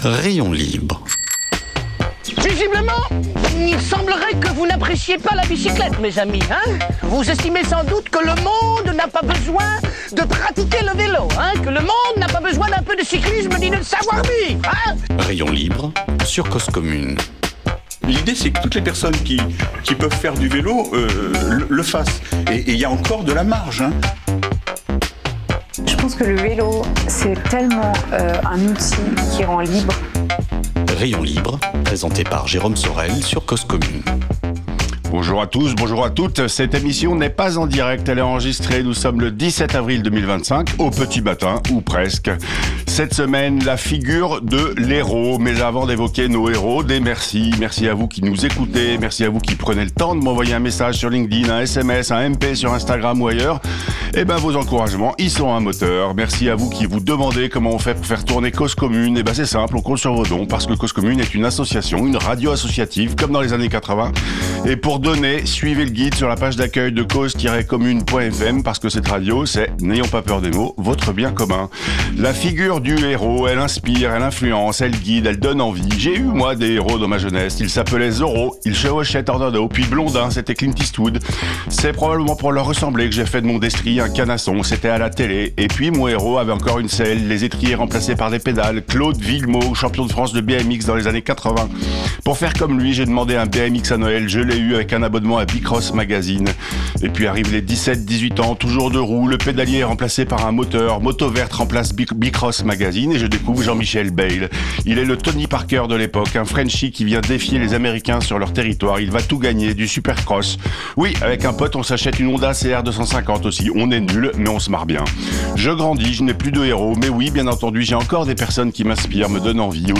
En plateau – Jean-Michel Bayle Prêt à partir.